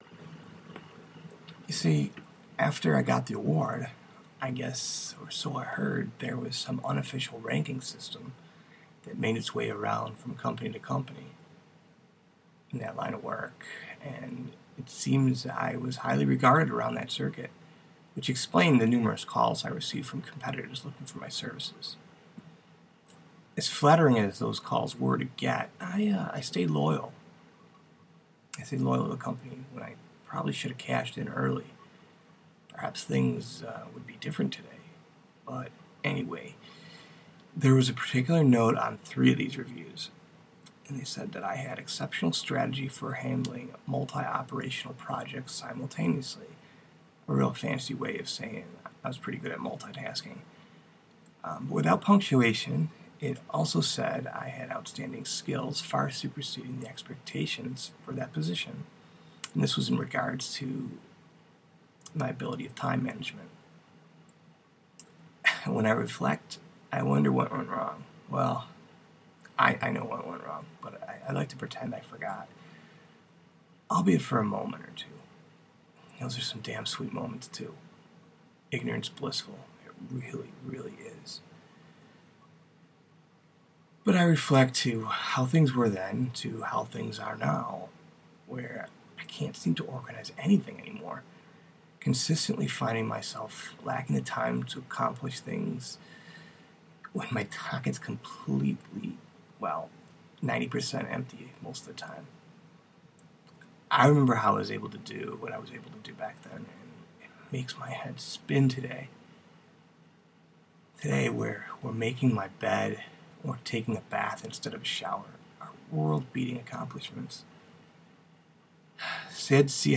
reading, recording, conversational, prose, prose poetry, past event, relationship to the present, reflections